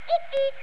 Der Kuckuck